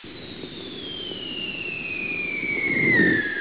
bombfall.wav